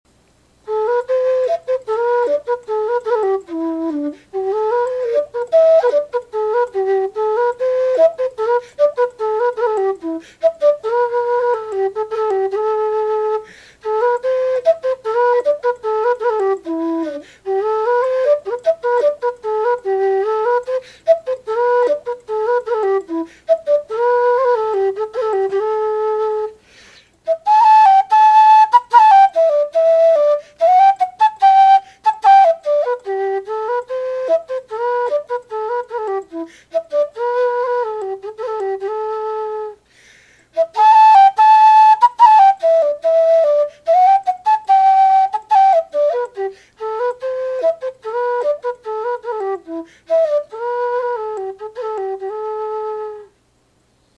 Whistle Reviewed: Kerry Songbird non-tunable Low D
Bottom Line: Similar to the New Range Chieftain Low D, though more rich and mellow.
But the Songbird low D is mellower than the Chieftain.
The Songbird has a richer timbre, with a bit more airiness and a bit less volume.
A sound clip of the whistle:
Volume: This whistle is on the quiet side.
It’s got that great “turn the lights down low” romantic sound to it, while still being much easier to play than the original Chieftains.